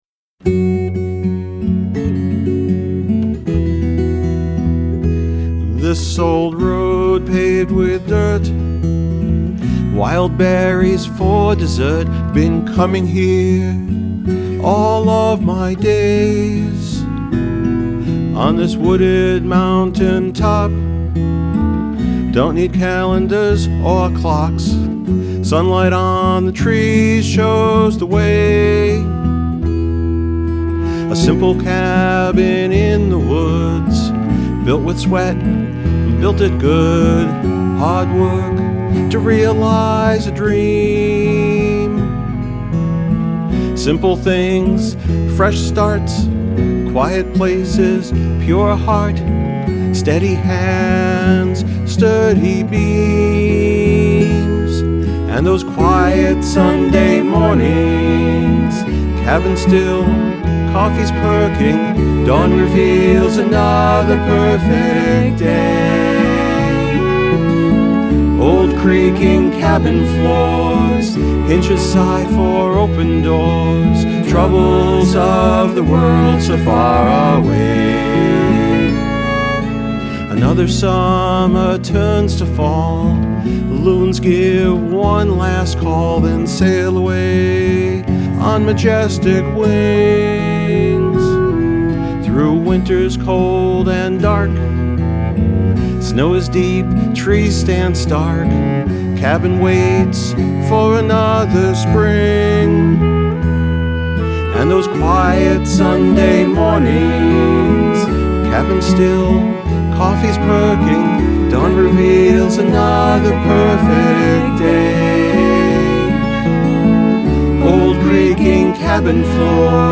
Genre: Country & Folk.